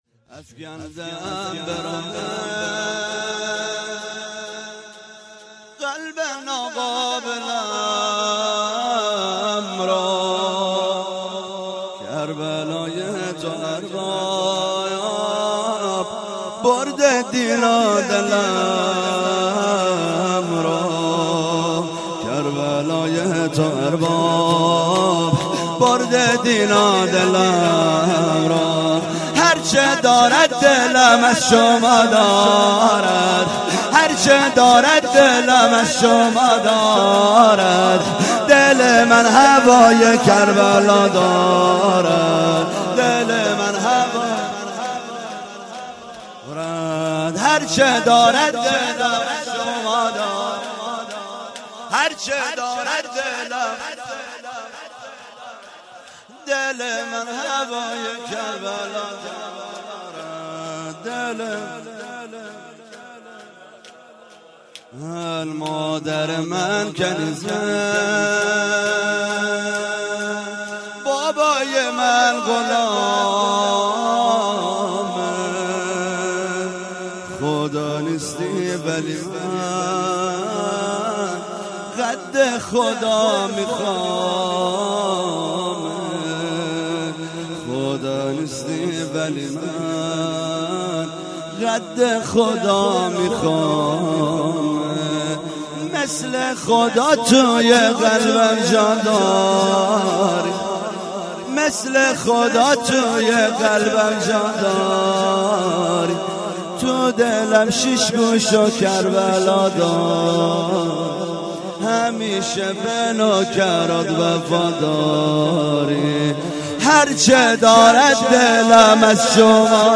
1 0 شور - افکنده ام به راهت
هفتگی - وفات حضرت ام البنین س - جمعه 11 اسفند